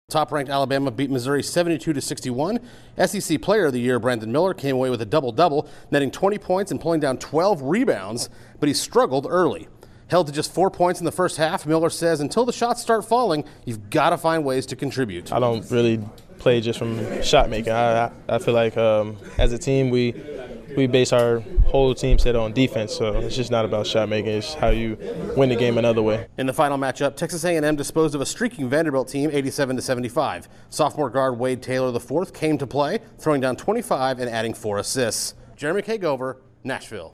The SEC title game will be a matchup of ranked teams. Correspondent